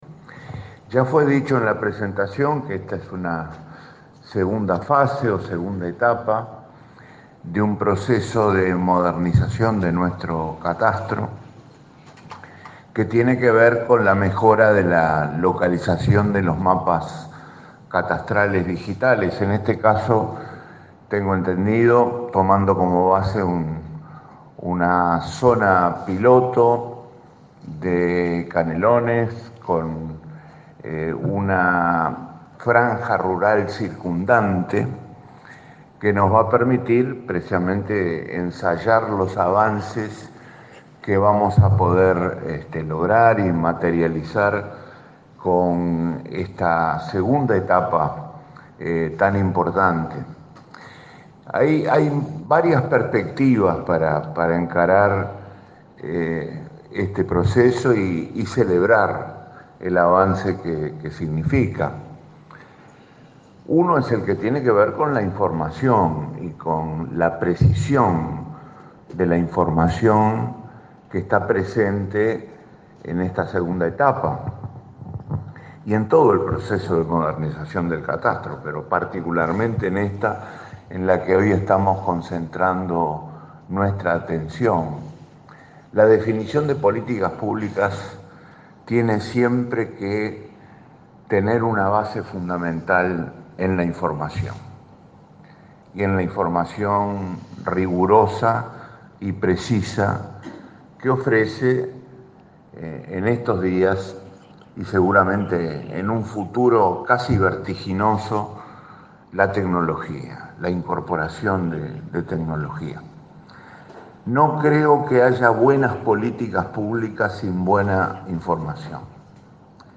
“No creo que haya buenas políticas públicas sin buena información”, aseguró el ministro de Economía, Danilo Astori, durante la presentación de la segunda etapa de la modernización del catastro. Se trata de la mejora de la localización de los mapas catastrales digitales en una zona de Canelones que ya está en funcionamiento, donde se utilizan imágenes satelitales para elaborar una cartografía de precisión.